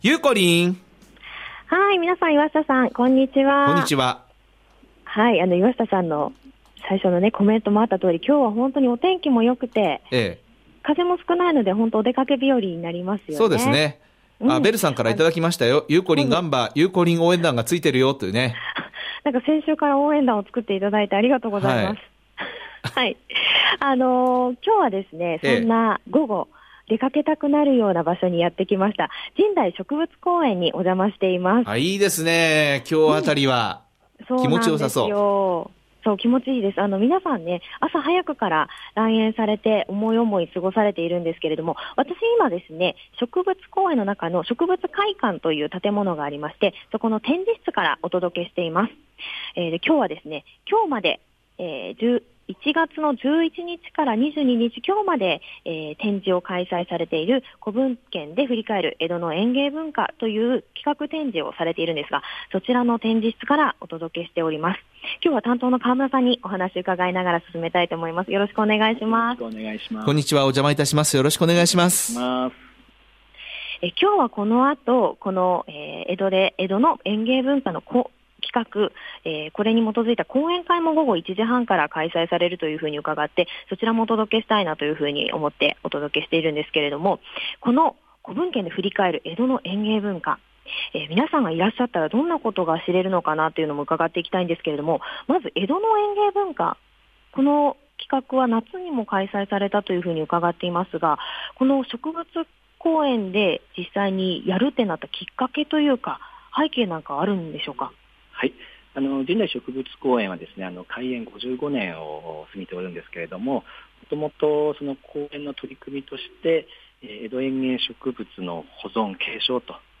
★びゅーサン 街角レポート
今日の街角レポートは、神代植物公園にやってまいりました！ 1月11日～本日まで「古文献でふりかえる江戸の園芸文化」が展示室で開催されており、 最終日の今日、お邪魔してきました！